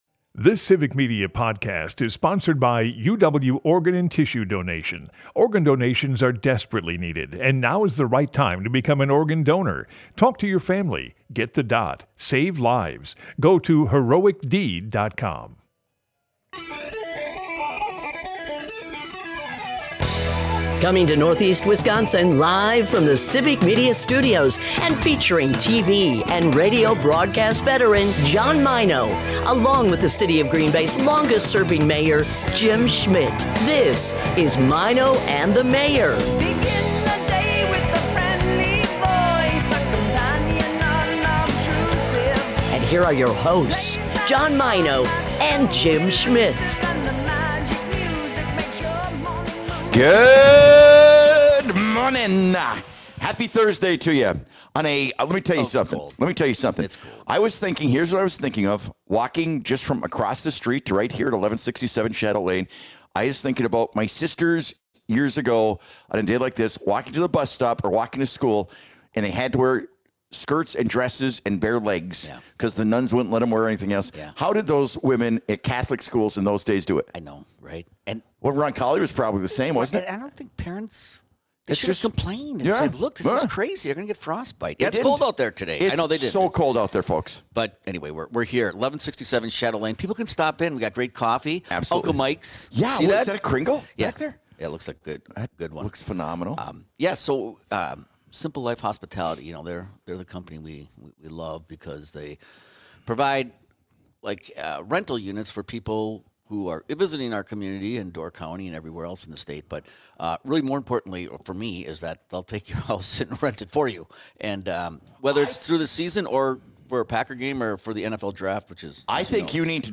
The guys hit the road this morning with Simple Life Hospitality to talk about rental properties for the NFL Draft, Packer games, and simple long weekend getaways! We're live right across Lombardi Avenue in the shadow of Lambeau Field!